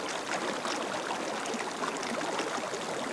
river2.wav